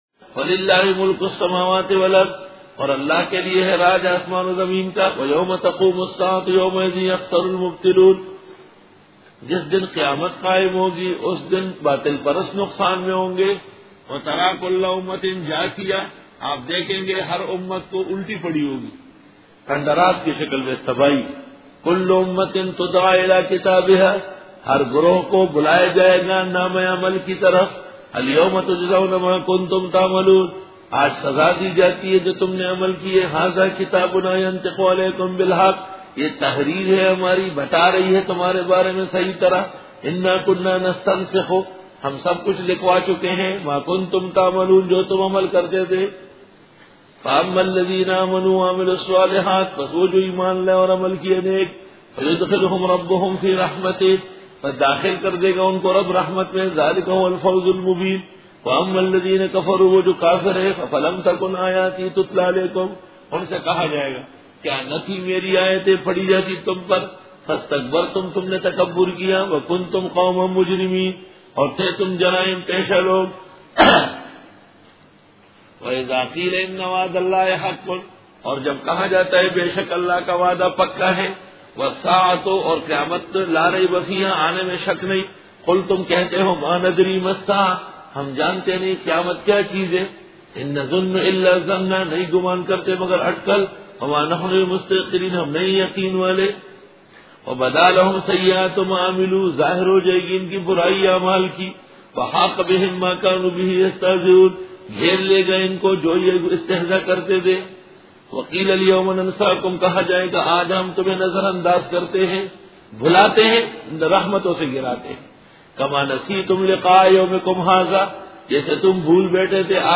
Dora-e-Tafseer 2004